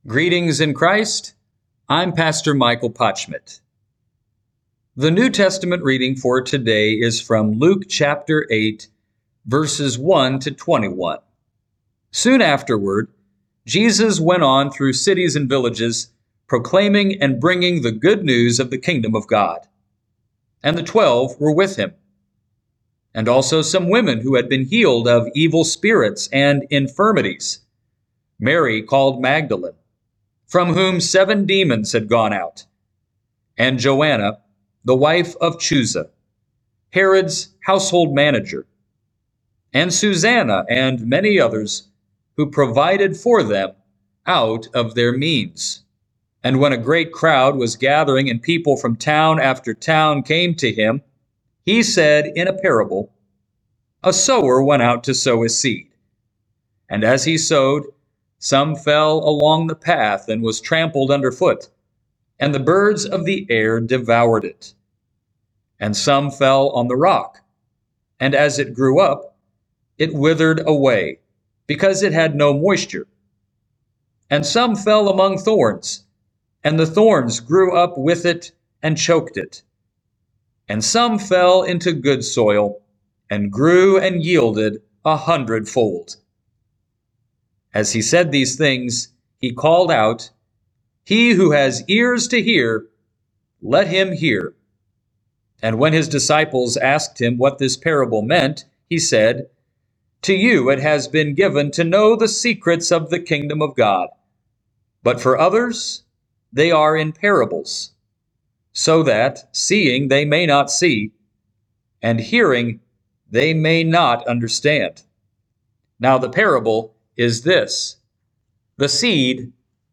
Morning Prayer Sermonette: Luke 8:1-21
Hear a guest pastor give a short sermonette based on the day’s Daily Lectionary New Testament text during Morning and Evening Prayer.